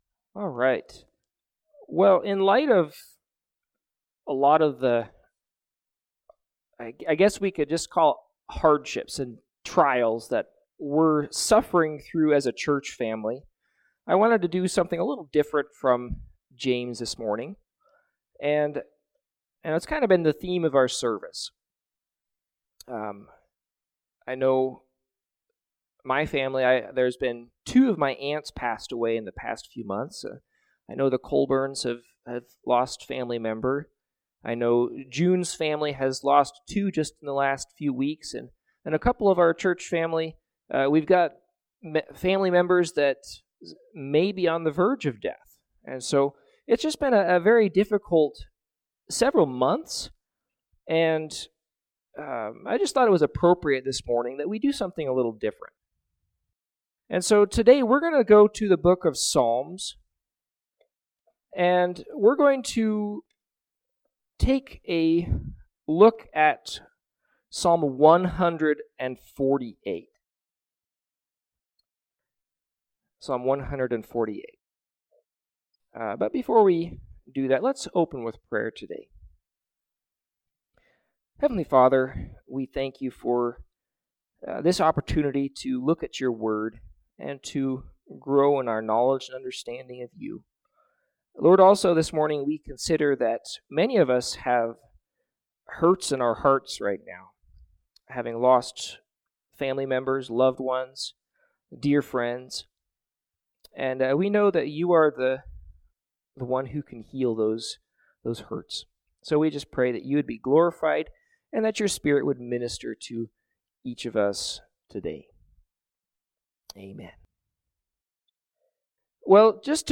Service Type: Morning Sevice